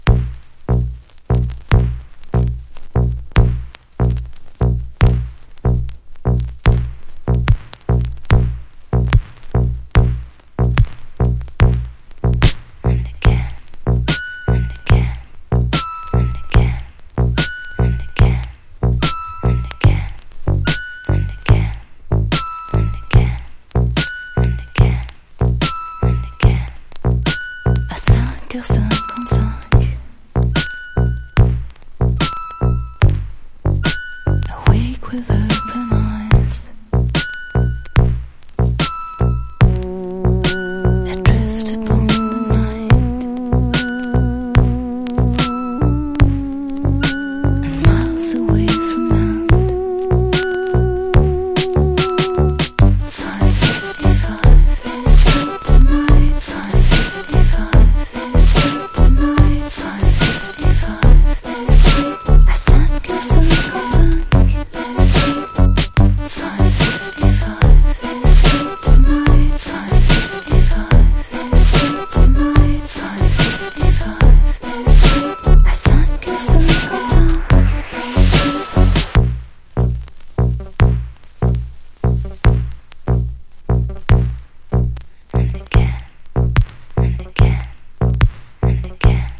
INDIE DANCE# ELECTRO POP# DOWN TEMPO